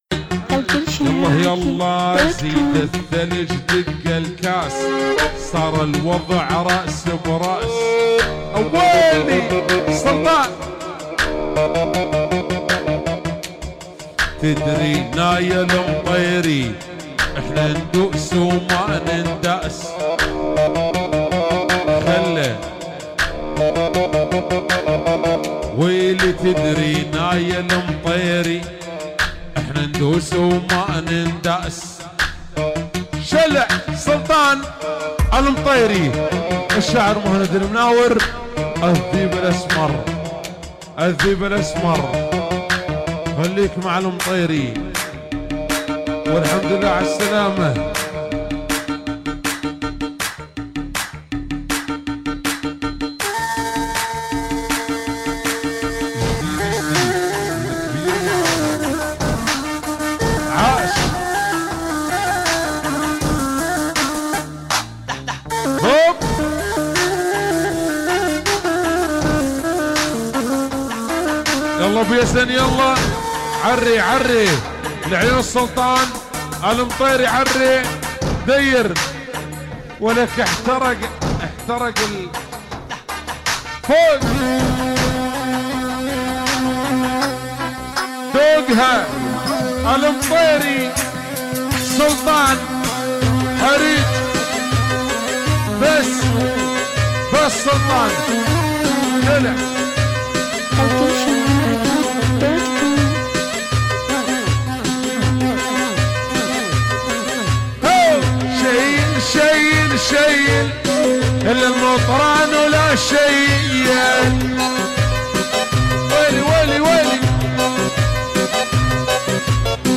دبكات مطلوبه اكثر شي